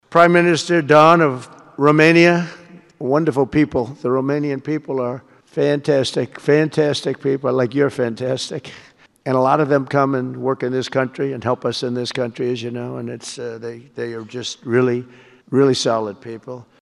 Este în desfășurare, la Washington, prima reuniune a Consiliului pentru Pace, for înființat de președintele american Donald Trump.
În cadrul discursului, liderul de la Casa Albă a vorbit despre fiecare dintre cei prezenți.
„Prim-ministrul Dan din România”, a spus Trump, referindu-se la președintele Nicușor Dan.